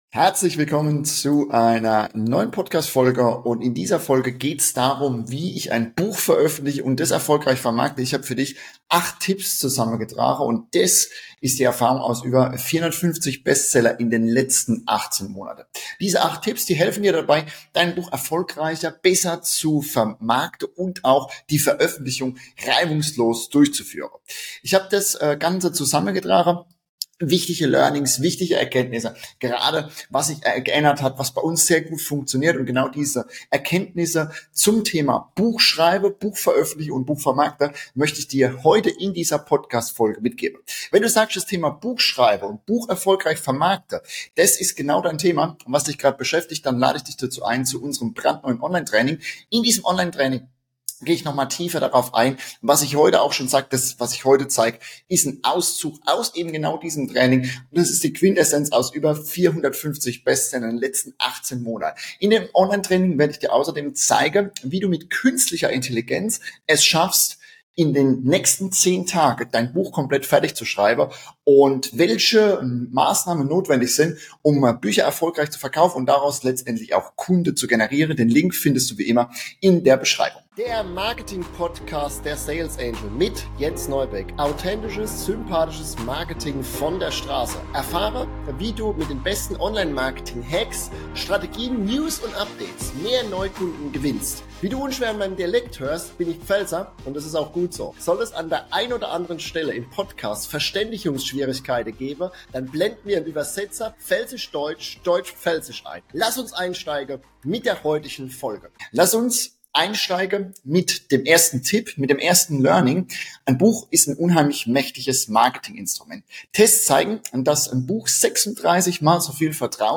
Ein ehrlicher Austausch mit vielen wertvollen Learnings.